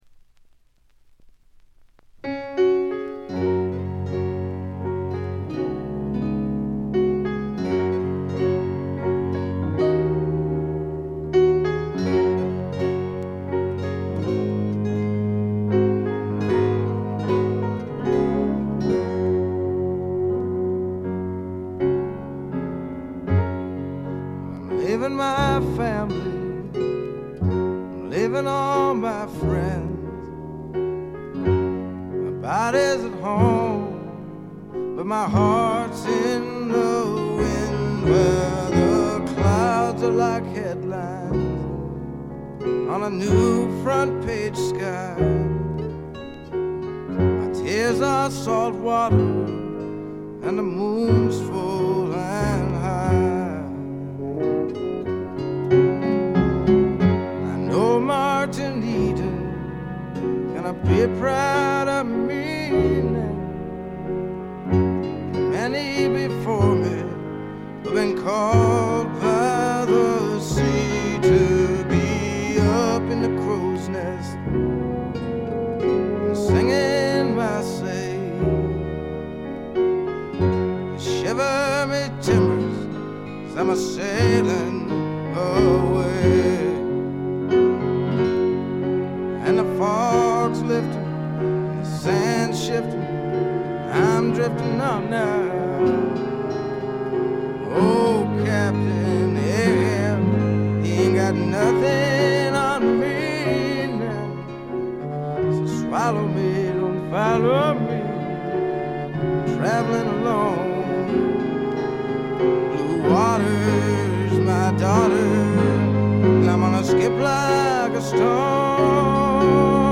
軽微なチリプチ程度。
メランコリックでぞっとするほど美しい、初期の名作中の名作です。
試聴曲は現品からの取り込み音源です。
vocals, piano, guitar